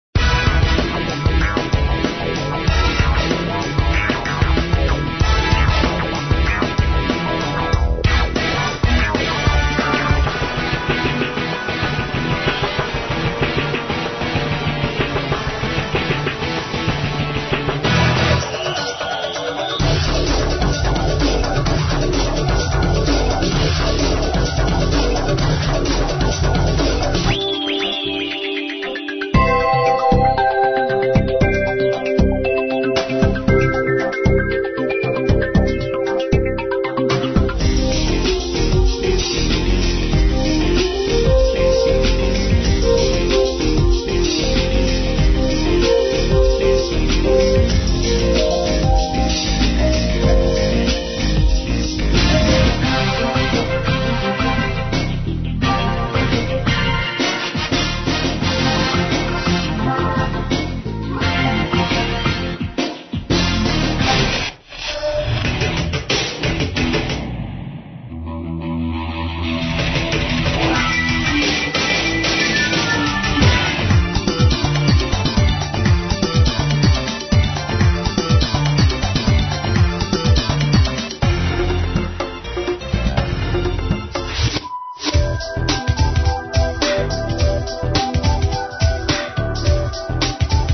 Музыкальная  библиотека  для  телевидения  и  радиовещания
Новогодние джинглы, отбивки, логии, кольца, …
запись для интернет, в низком (неэфирном) качестве!